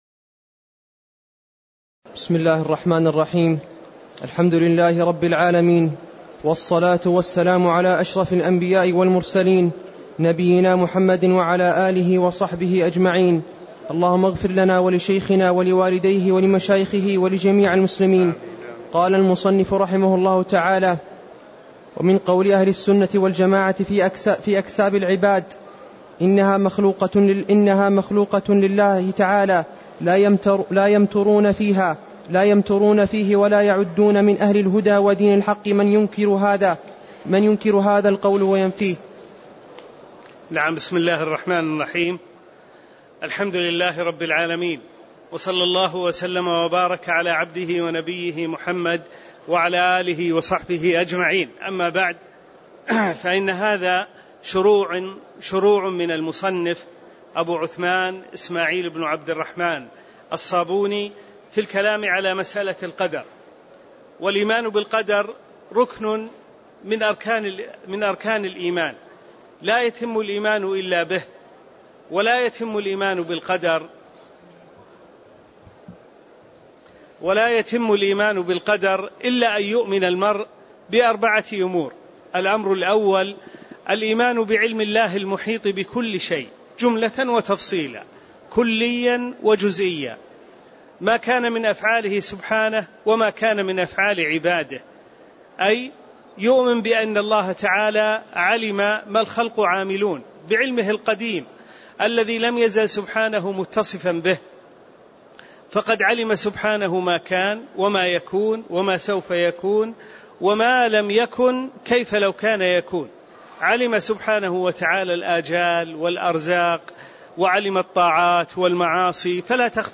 تاريخ النشر ٢٥ شعبان ١٤٣٦ هـ المكان: المسجد النبوي الشيخ